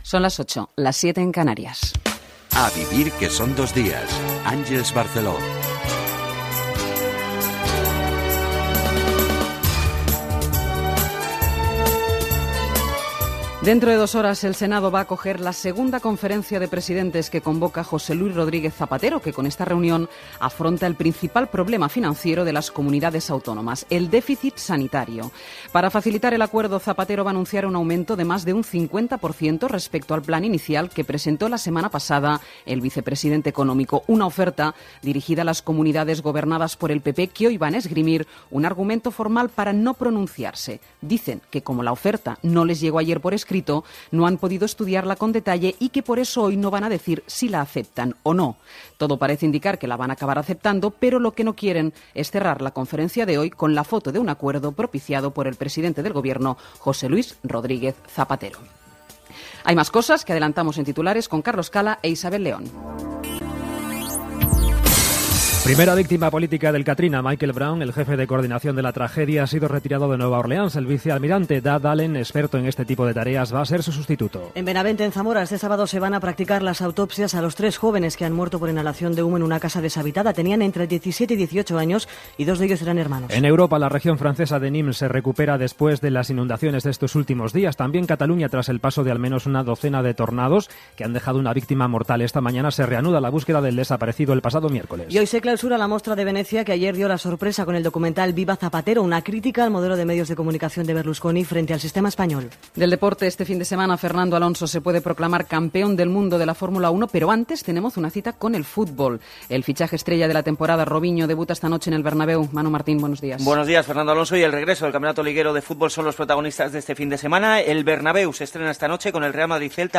Primer programa presentat per Àngels Barceló a la Cadena SER.